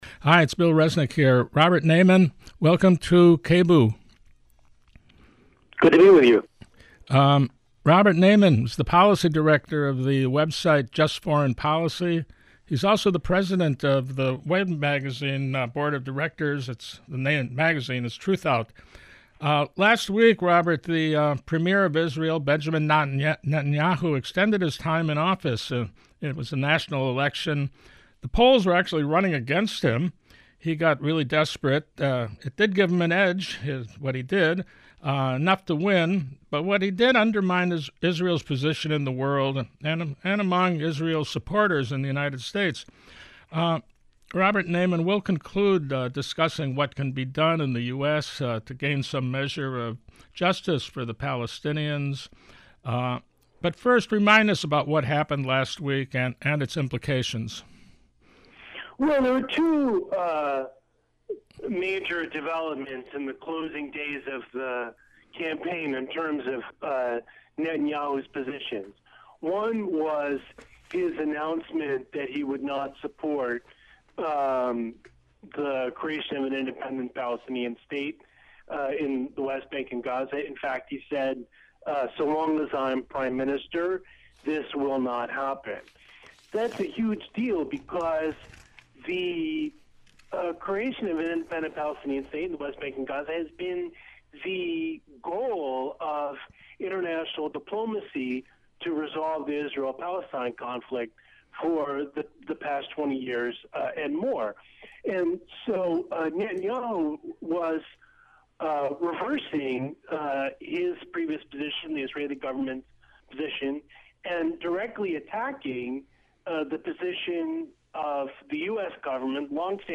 Discussion of Israeli elections and those affected by them